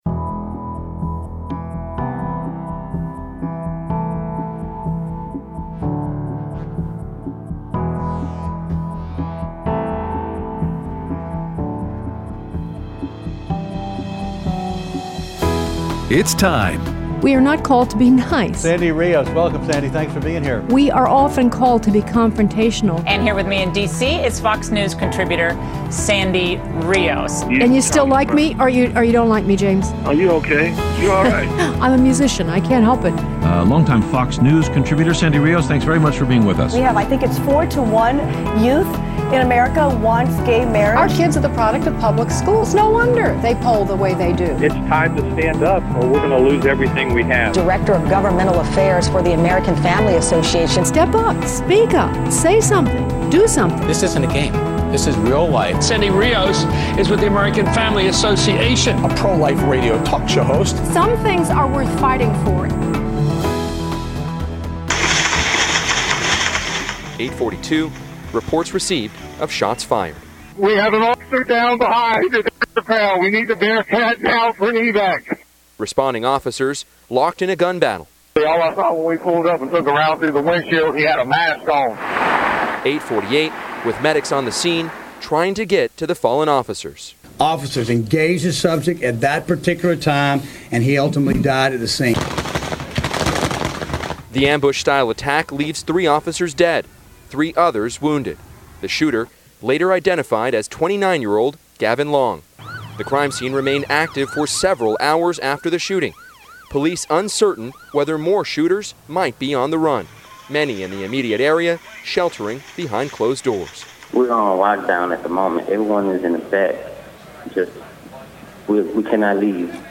Live at the RNC interviewing Mark Krikorian and Cong. Louie Gohmert